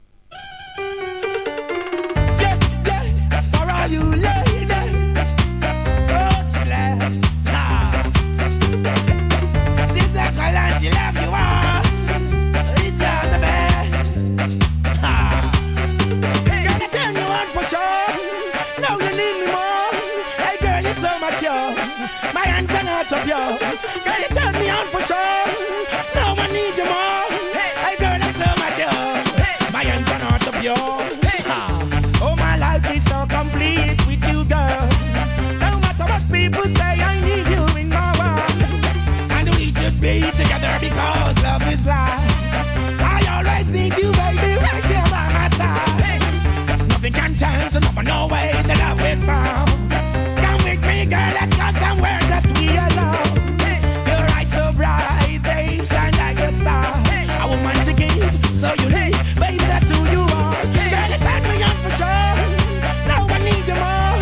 REGGAE
SKAナンバー